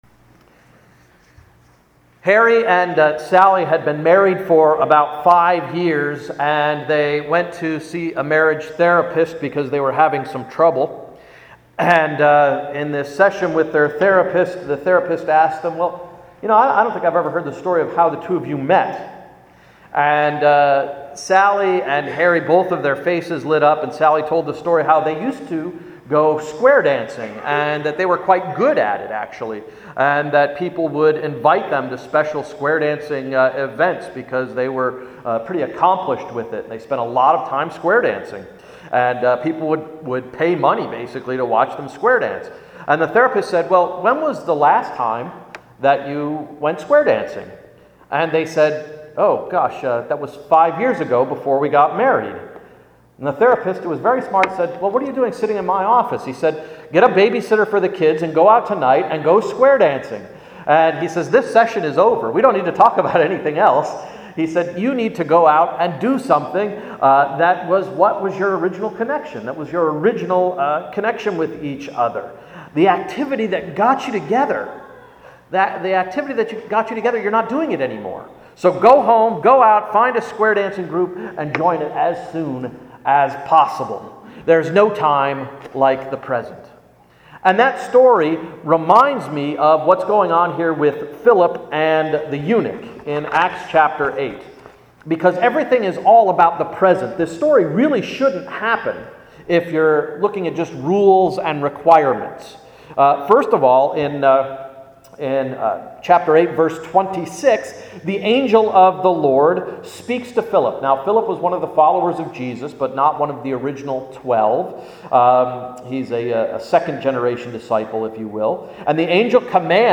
Sermon of May 6, 2012–No Time Like the Present